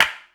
Clap [2].wav